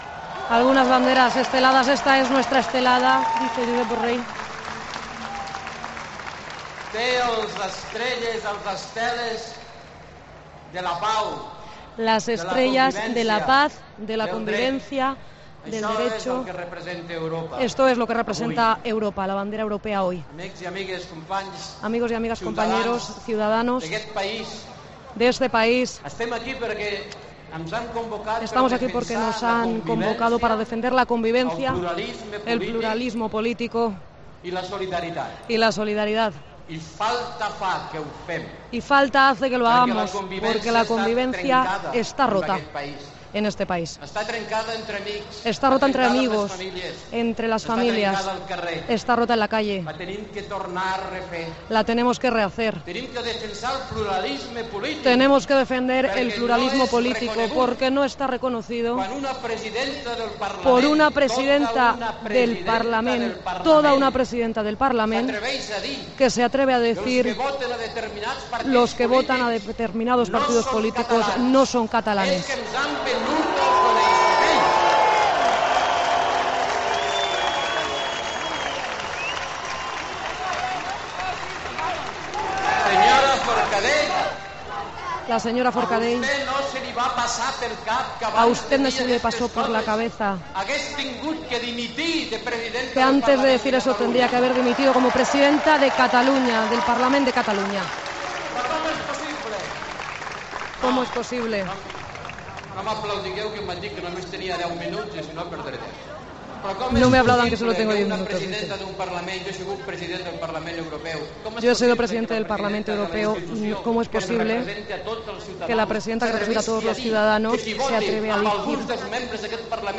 Al término de la manifestación por la unidad de España convocada en Barcelona por la entidad Societat Civil Catalana , Borrell ha alertado de que Cataluña está viviendo "momentos casi dramáticos" y ha pedido a Puigdemont que vaya con "mucho cuidado".